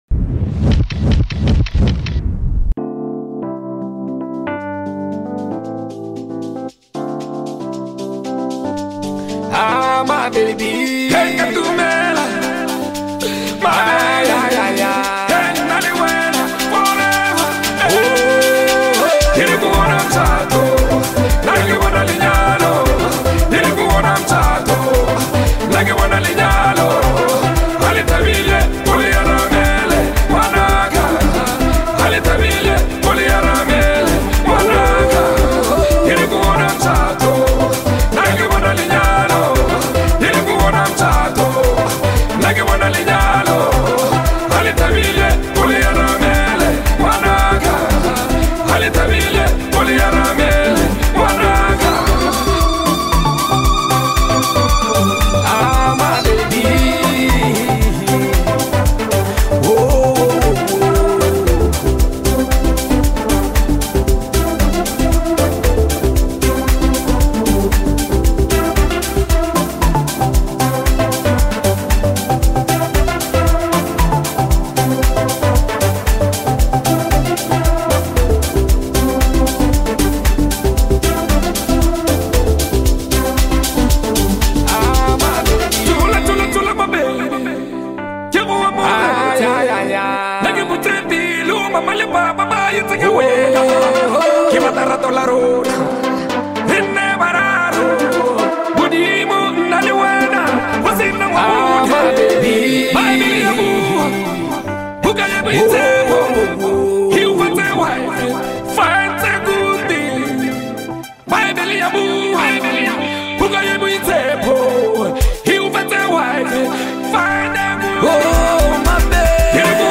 smooth rhythm, catchy flow